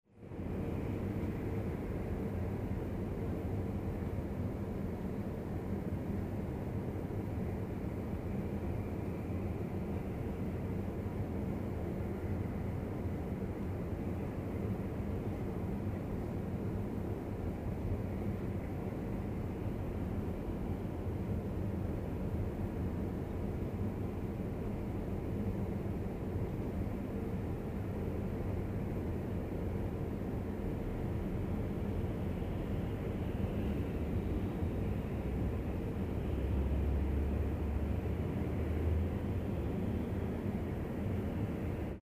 EXTERIOR ABIERTO AIRE
Ambient sound effects
exterior_abierto_aire.mp3